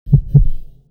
heartbeat_edit.mp3